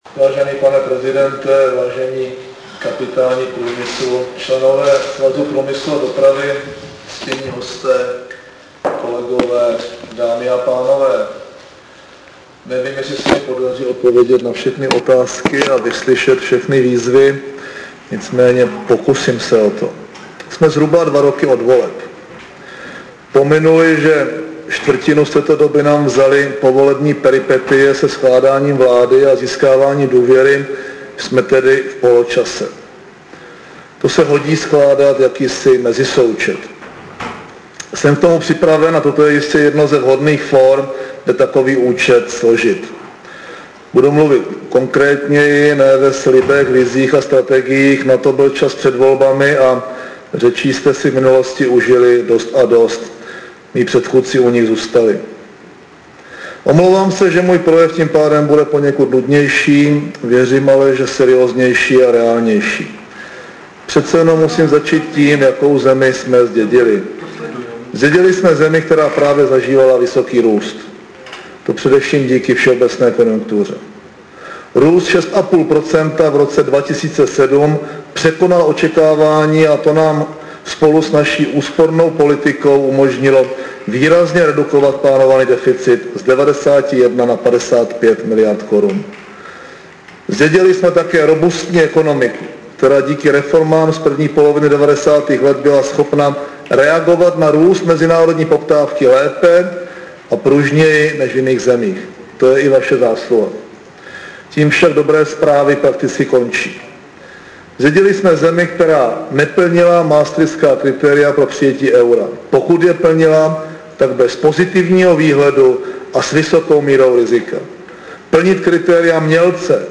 Předseda vlády M. Topolánek ve svém projevu na slavnostním zahájení brněnského veletrhu zdůraznil nutnost odpovědného přístupu ke stanovení data přijetí jednotné evropské měny.